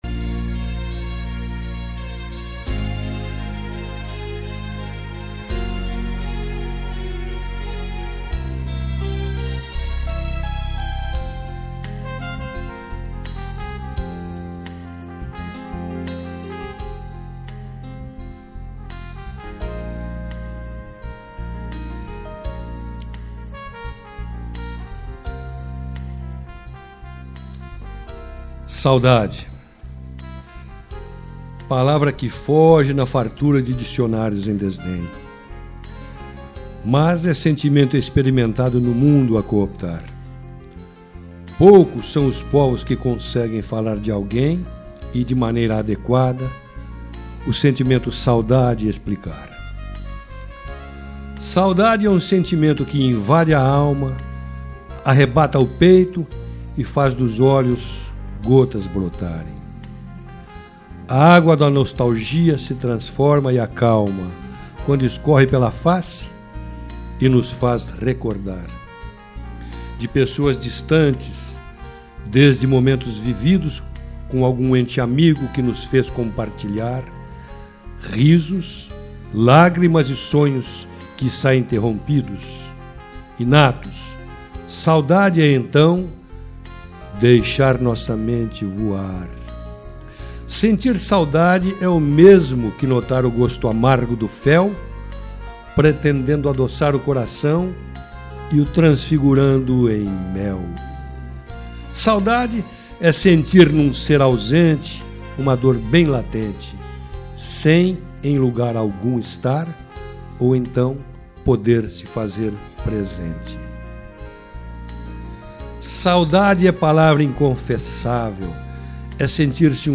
interpretação do texto